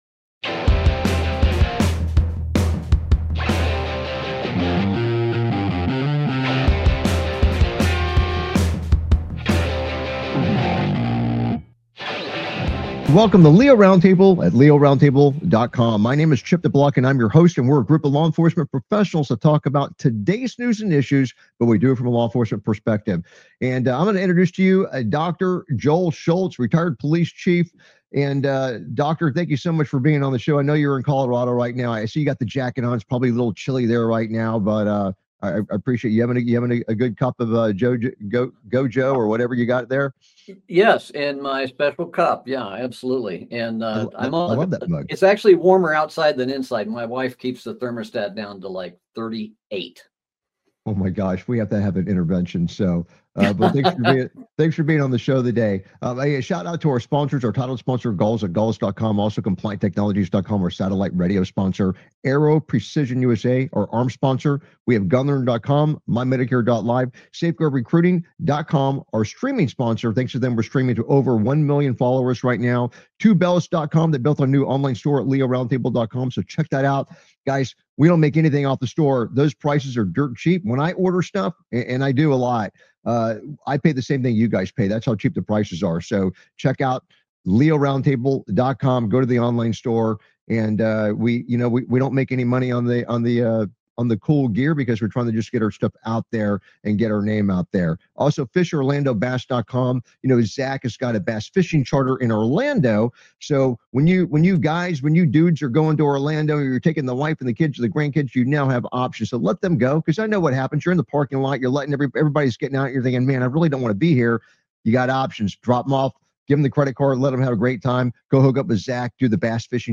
LEO Round Table Talk Show